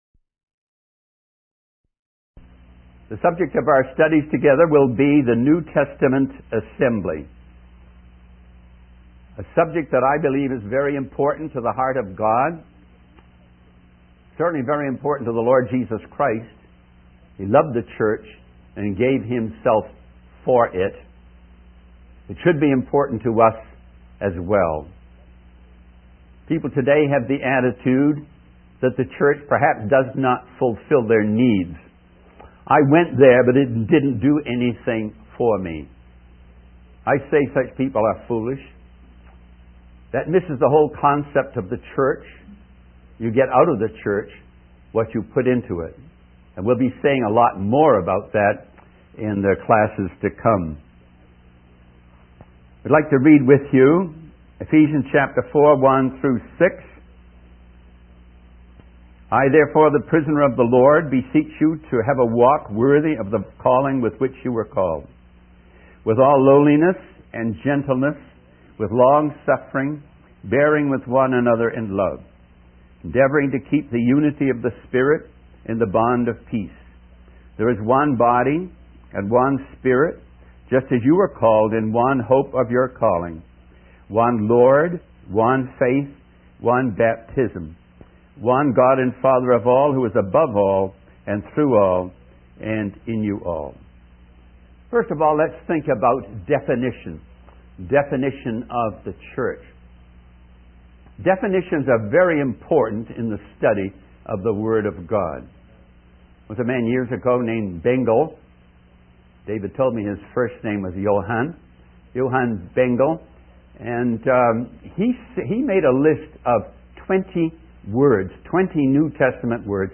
The speaker reads from Ephesians 4:1-6, highlighting the importance of unity and love within the Church. He also emphasizes the need for the Church to be a light in a dark world and to have a living faith that reaches out to others.